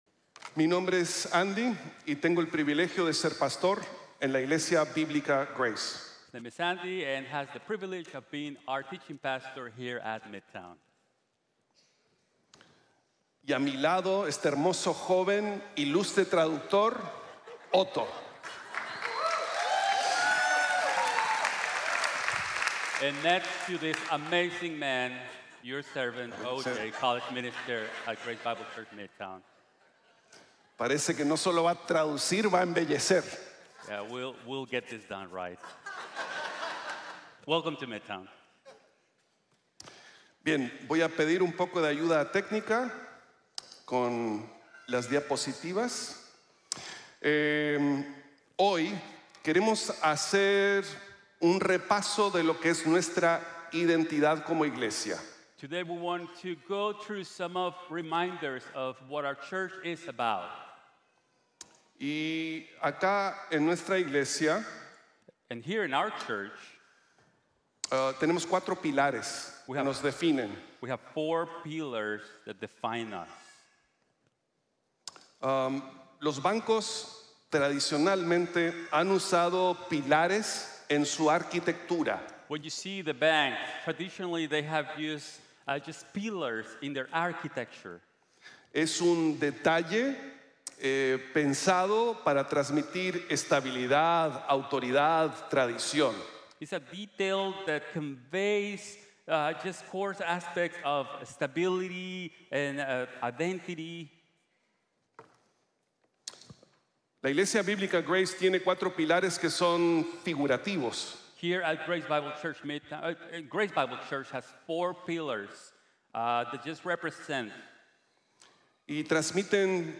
God's Grace to Every Nation | Sermon | Grace Bible Church